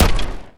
sci-fi_weapon_pistol_shot_02.wav